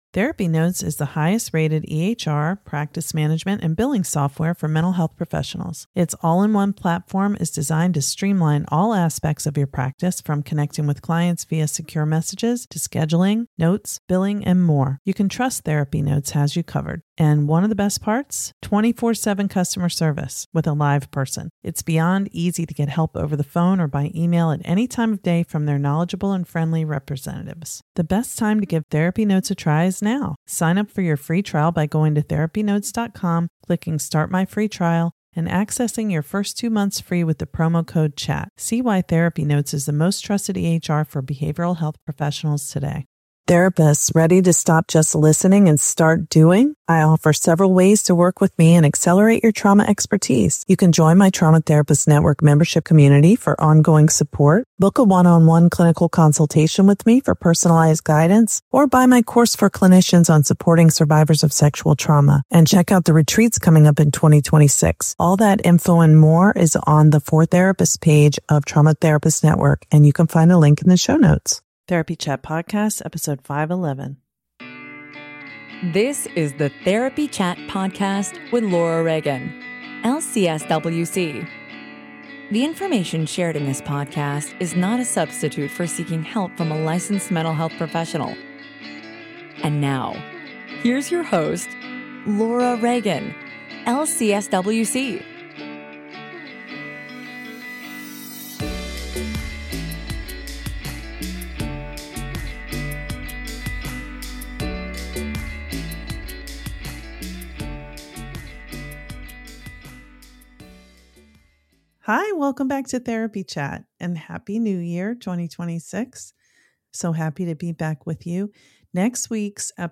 Q&A episode